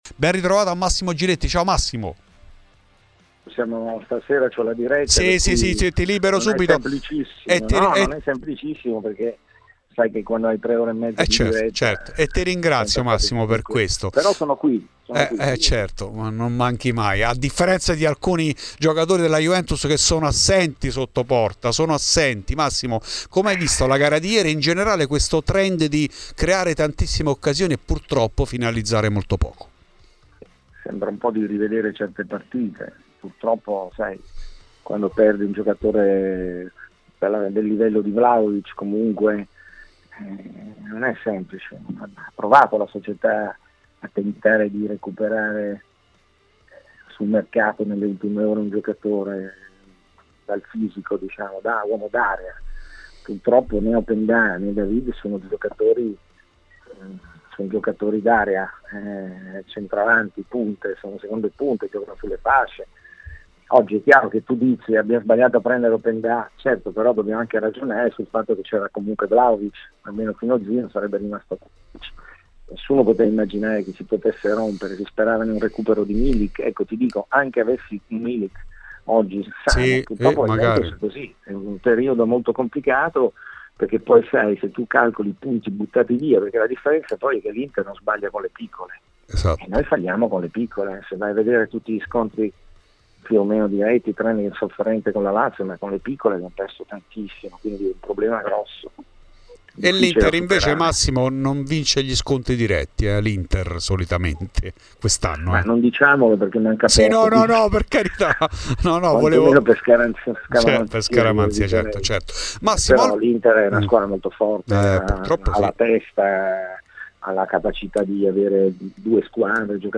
A Radio Bianconera è intervenuto il giornalista e tifoso doc della Vecchia Signora Massimo Giletti, che è partito ovviamente dal pari contro la Lazio: "Sembra di rivedere certe partite.